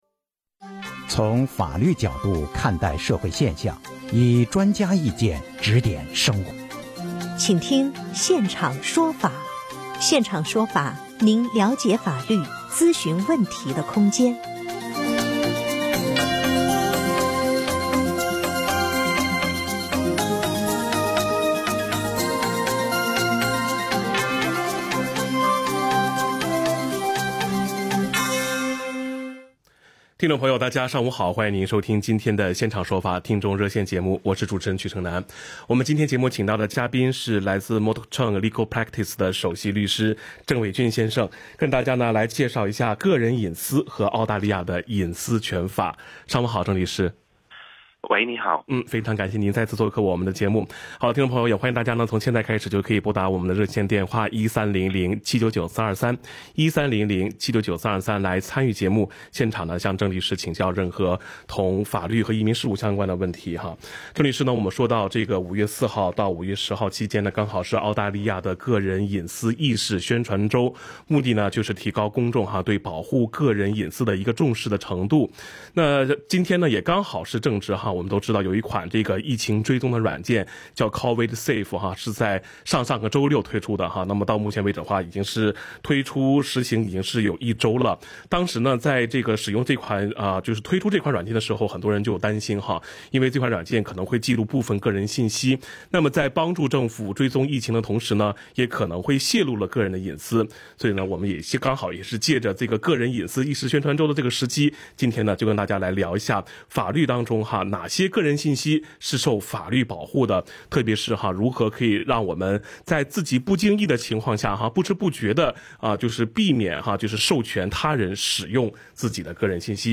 legal_talkback_privacy_new.mp3